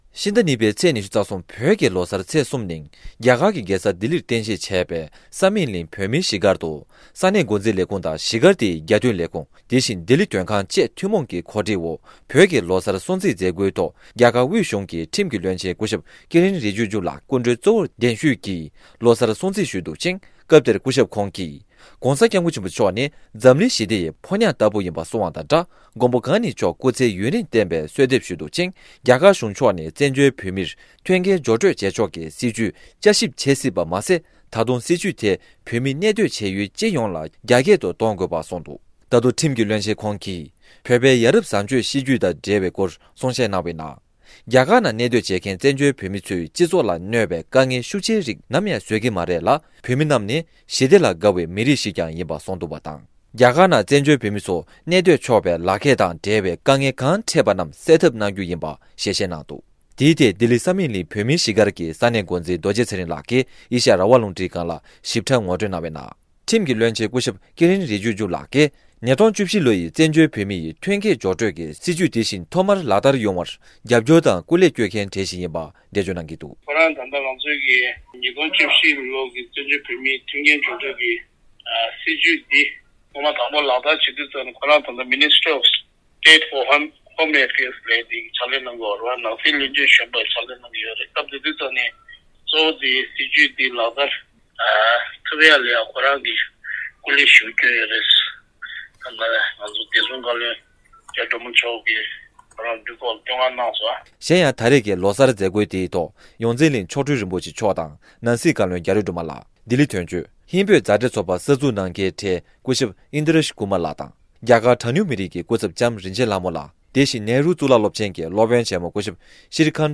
བཙན་བྱོལ་བོད་མིར་མཐུན་རྐྱེན་སྦྱོར་སྤྲོད་བྱེད་ཕྱོགས་ཀྱི་སྲིད་བྱུས་ཡར་རྒྱས་བསྐྱར་ཞིབ་བྱེད་སྲིད་པ་རྒྱ་གར་ཁྲིམས་ཀྱི་བློན་ཆེན་མཆོག་གིས་གསུང་འདུག ལྡི་ལིར་བོད་ཀྱི་ལོ་གསར་སྲུང་བརྩིའི་མཛད་སྒོའི་སྐབས། ༠༢།༢༣།༢༠༢༣
སྒྲ་ལྡན་གསར་འགྱུར།